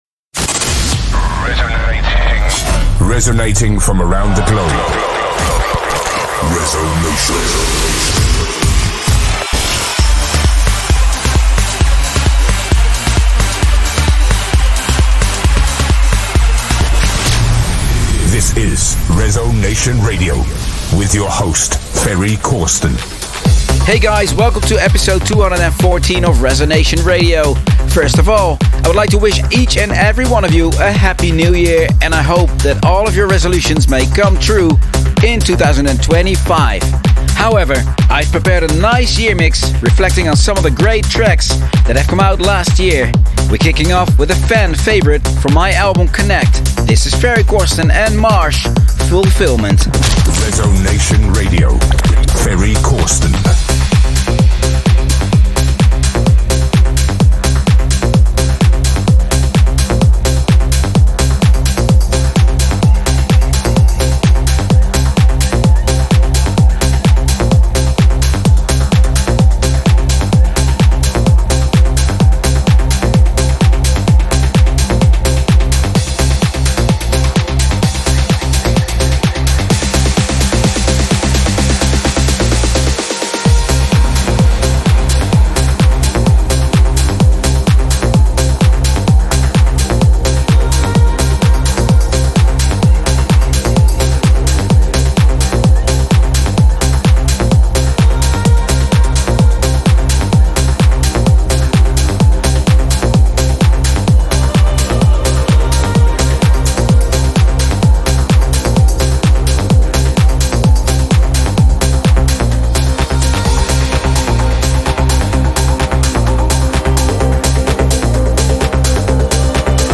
will be broadcast from a full studio set up
electronic music